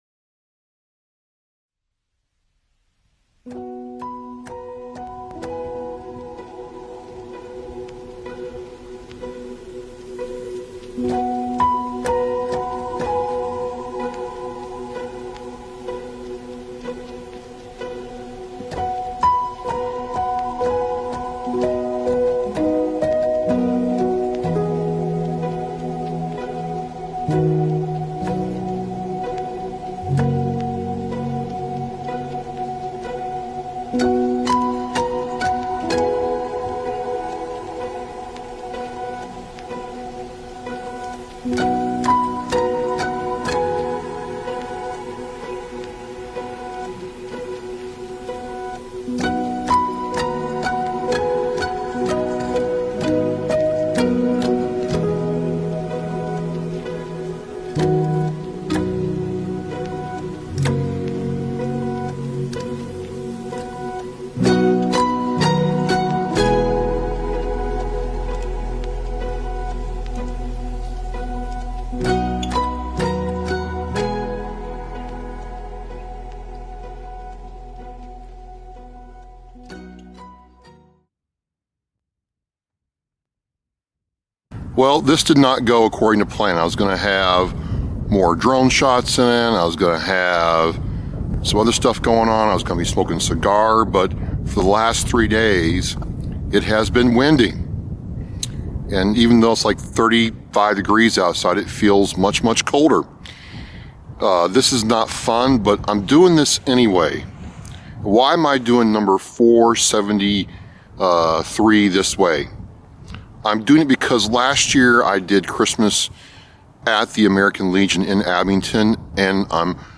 Recorded at Island Grove Park in Abington, MA, Episode 473 of Oscar Mike Radio was one for the books, cold, windy New England weather and all!